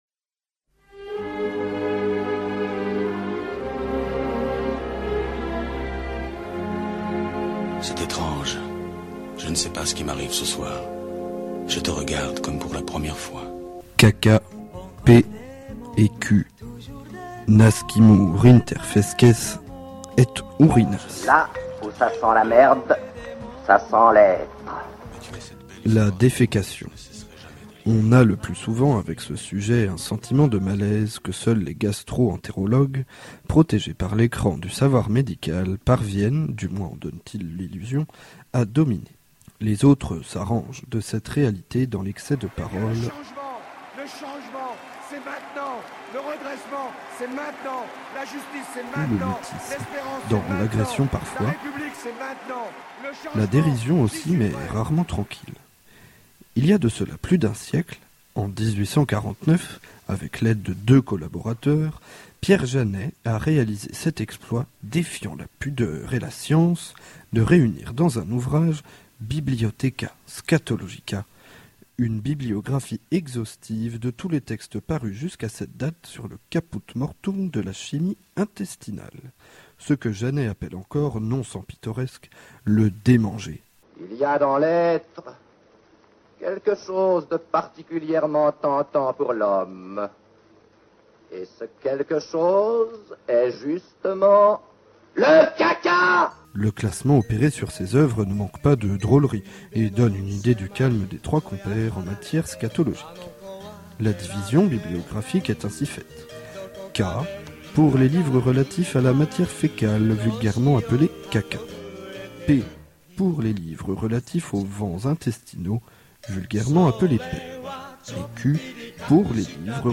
Avec les enregistrements en question, nous avons concocté des pièces sonores, avec adjonction de sons, musiques ou extraits de films… Enjoy!
Le Caca  (A base de : discours politiques, chansons d’anthologie)